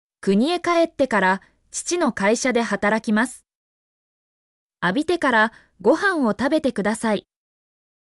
mp3-output-ttsfreedotcom-47_bzIEhSC8.mp3